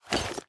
Minecraft Version Minecraft Version snapshot Latest Release | Latest Snapshot snapshot / assets / minecraft / sounds / item / armor / equip_iron3.ogg Compare With Compare With Latest Release | Latest Snapshot
equip_iron3.ogg